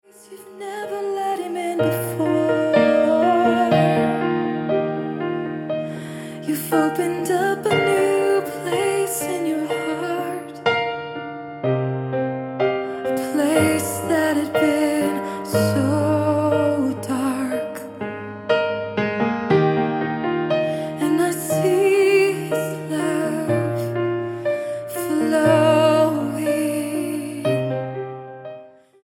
MOR / Soft Pop
Soaking Music